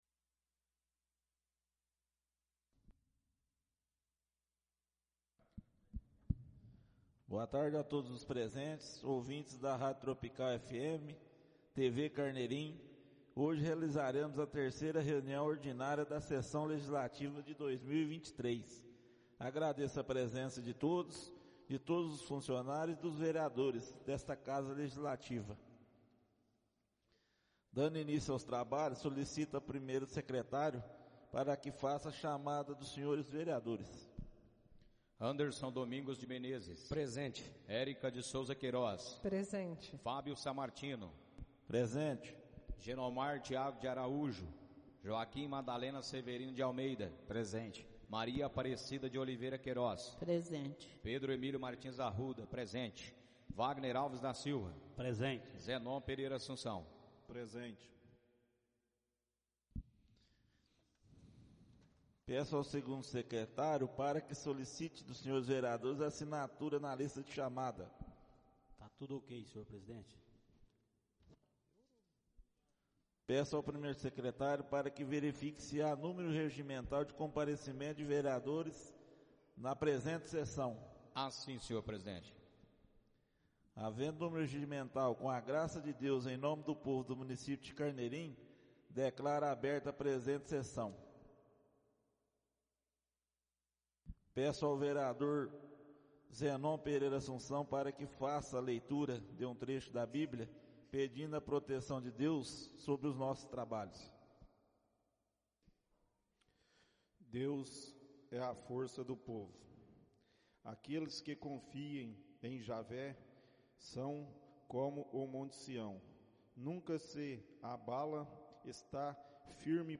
Audio da 3.ª reunião ordinária de 2023, realizada no dia 06 de Março de 2023, na sala de sessões da Câmara Municipal de Carneirinho, Estado de Minas Gerais.